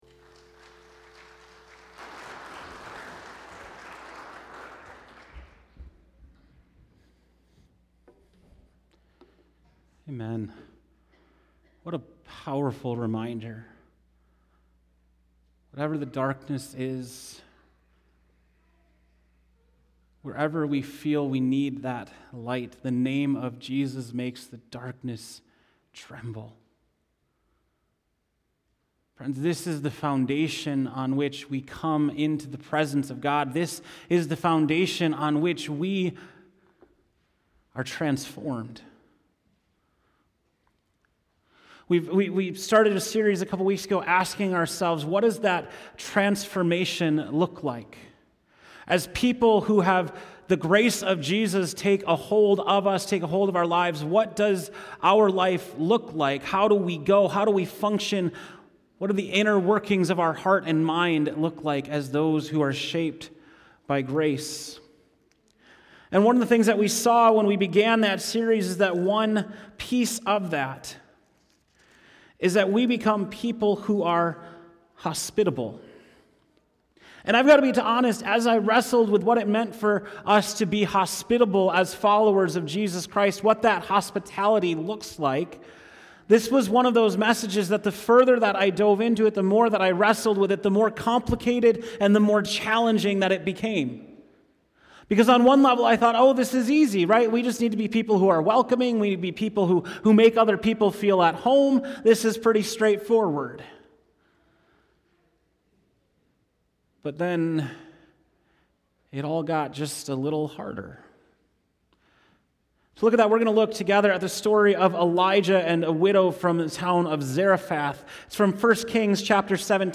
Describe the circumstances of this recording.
February 2, 2020 (Morning Worship)